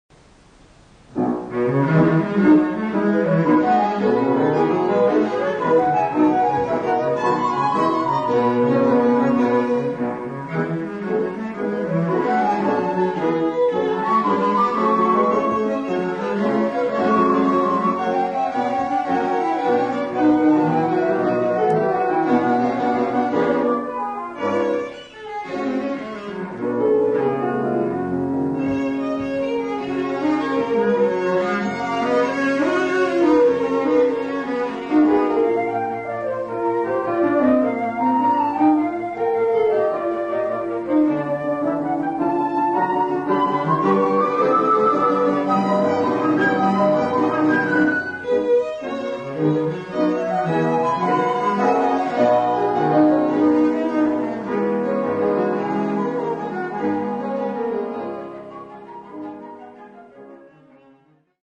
Muchas grabaciones que aquí se ofrecen se registraron en presentaciones en vivo durante las décadas de 1950, 1960 y 1970.
Divertimento para flauta, violín, violoncello y piano (1959).